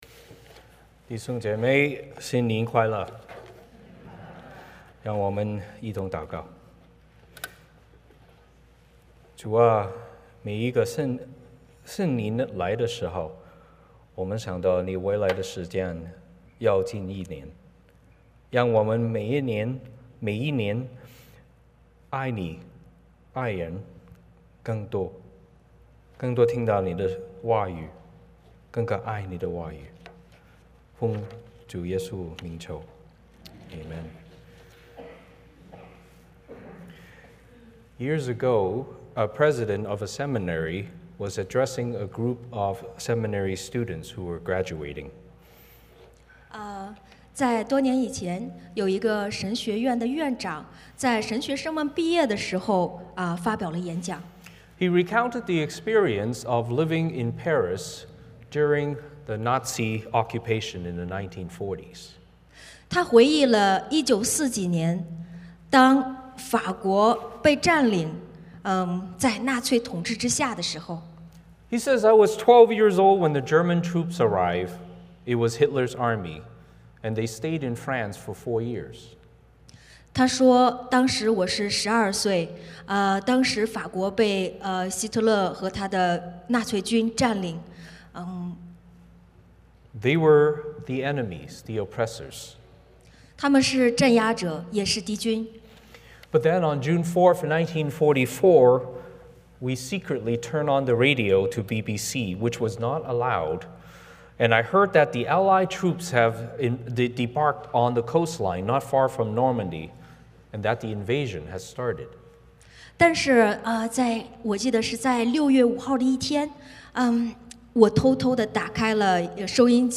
欢迎大家加入我们国语主日崇拜。
Service Type: 主日崇拜 欢迎大家加入我们国语主日崇拜。